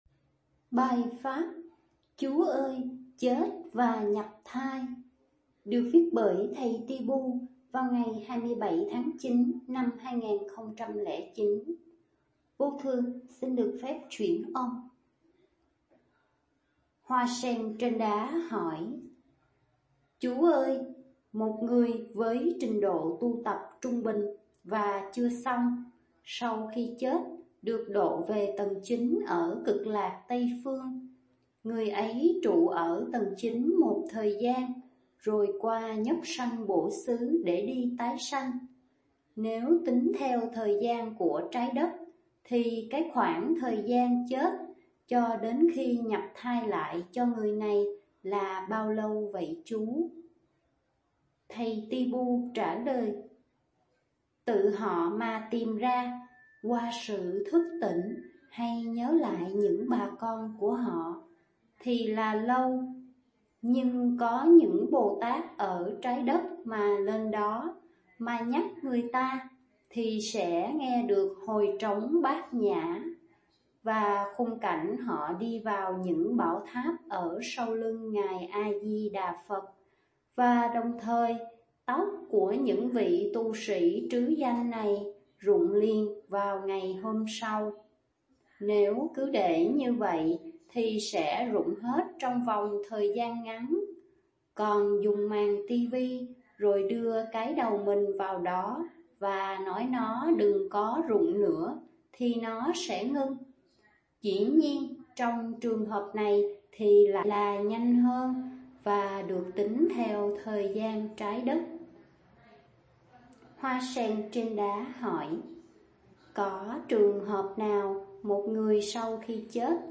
Hỏi về Chết và Nhập Thai (chuyển âm)